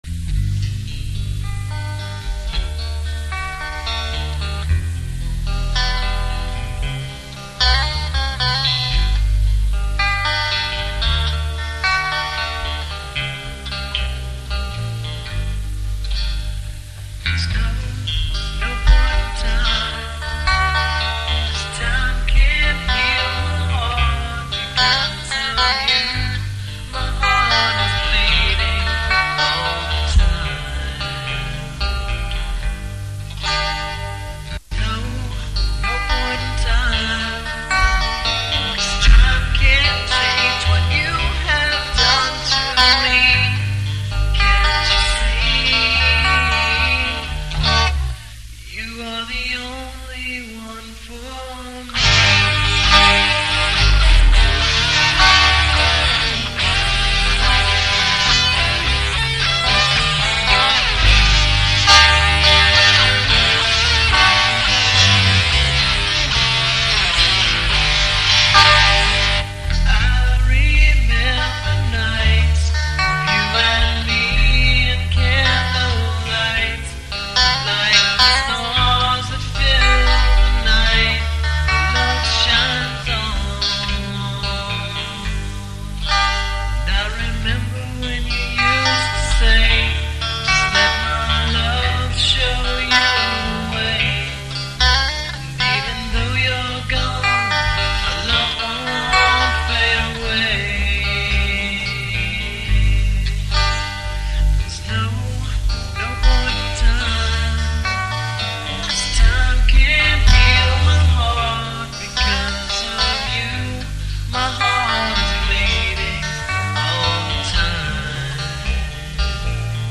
vocals
me on bass...I believe this was one of our 1st experiments with the four track...hence the sound isnt exactly as good as it could be